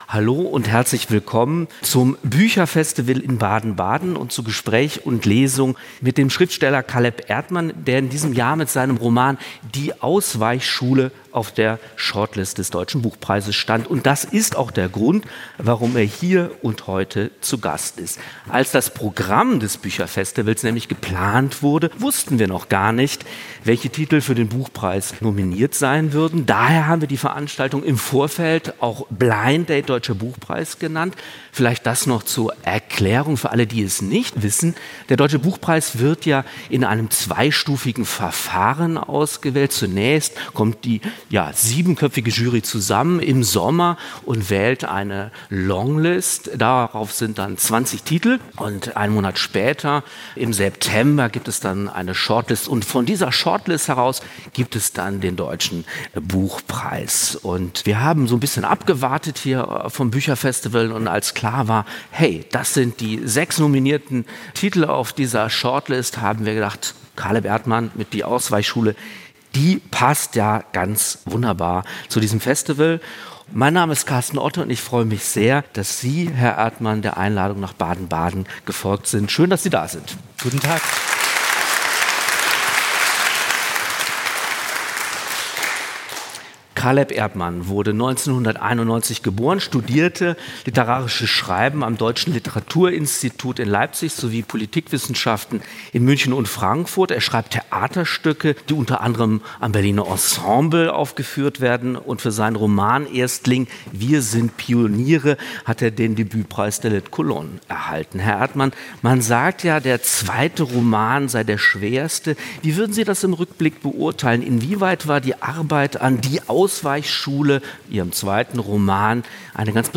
Deutscher Buchpreis zu Gast beim Baden-Badener Bücherfestival